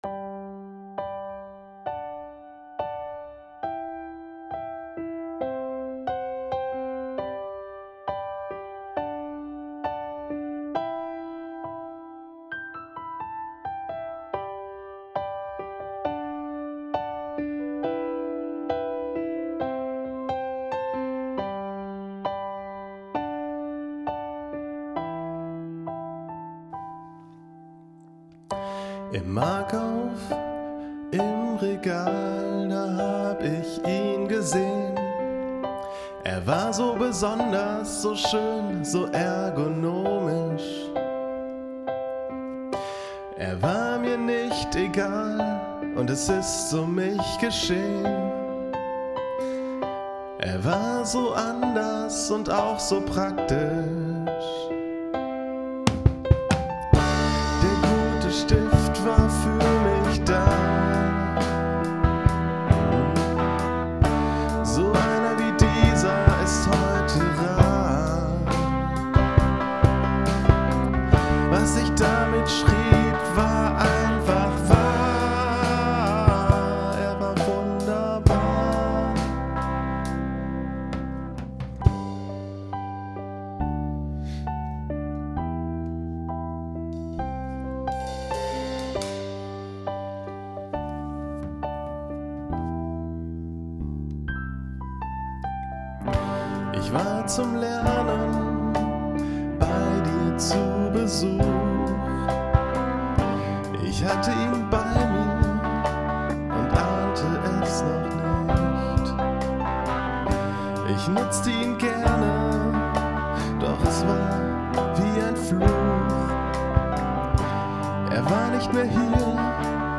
Es ging mir vor allem darum, meiner Kreativität freien Lauf zu lassen und gleichzeitig mein kleines Heimstudio auszuprobieren.
• Alles wurde an einem Tag geschrieben, gespielt, aufgenommen und produziert.